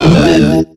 Cri de Tarinor dans Pokémon X et Y.